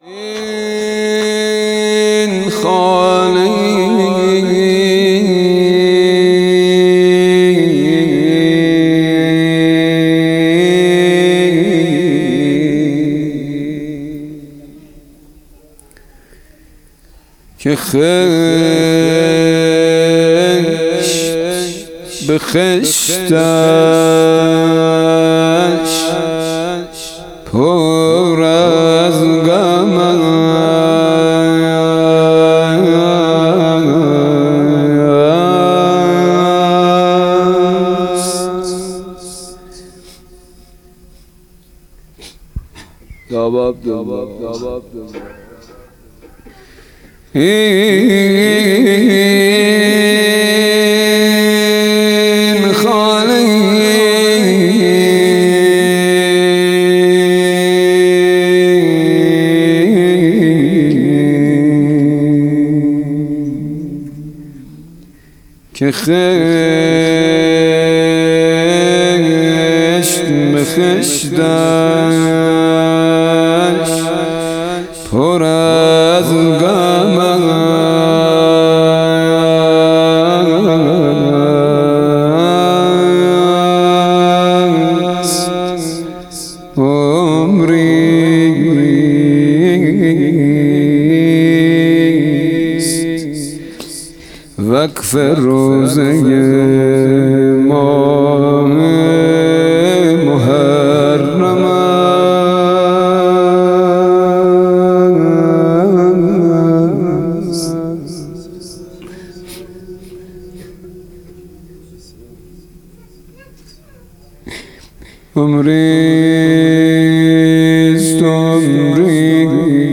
مراسم عزاداری پنج شب آخر صفر